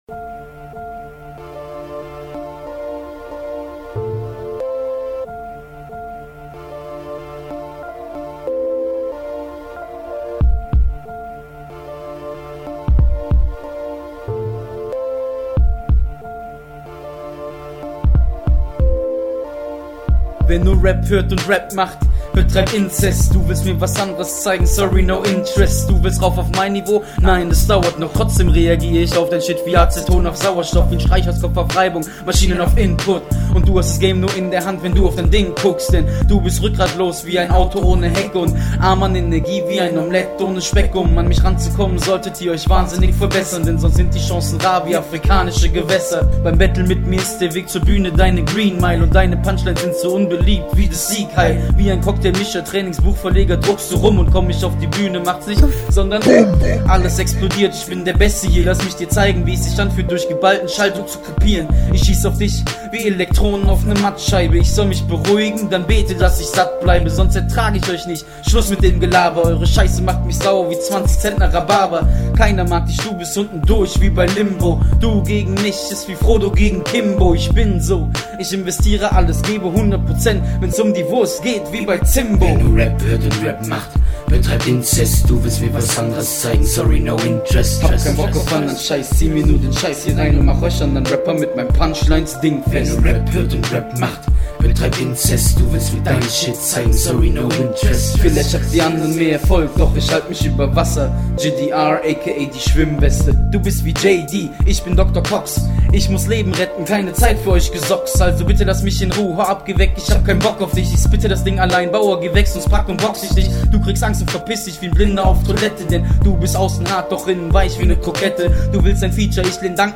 Mal wieder was etwas aggreroissivierenderes...